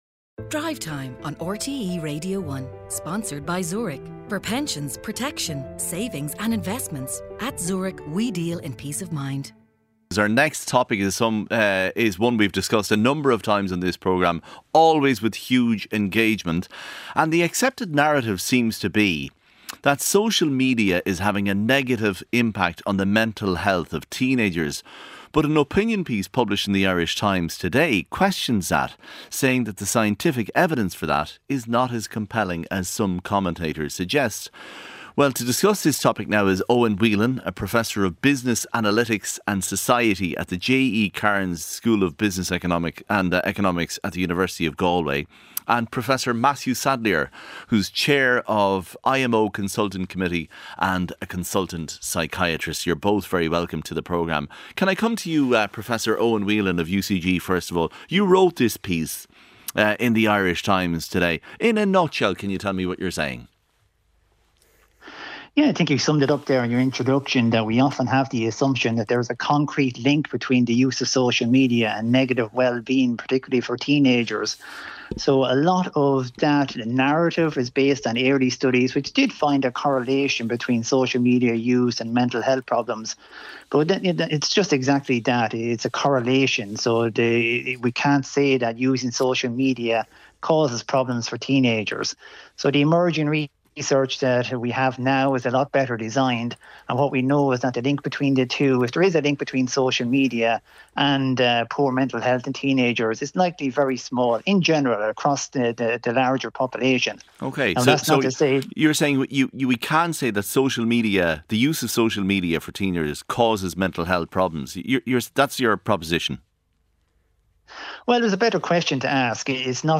Featuring all the latest stories, interviews and special reports.